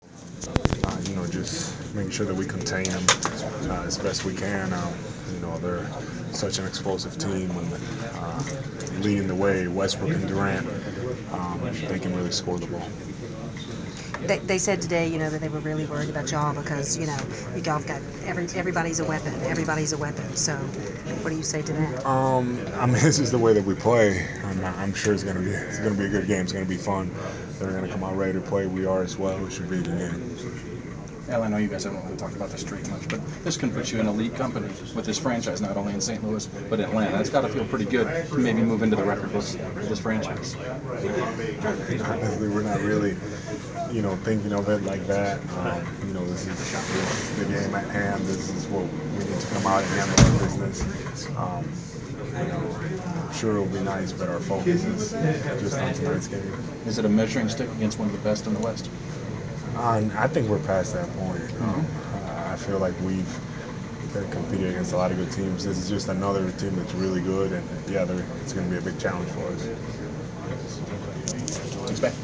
Inside the Inquirer: Pregame interview with Atlanta Hawks’ center Al Horford (1/23/15)
We caught up with Al Horford of the Atlanta Hawks before his team’s home contest against the Oklahoma City Thunder on Jan. 23. Topics included the Hawks’ 14-game winning streak and facing the Thunder.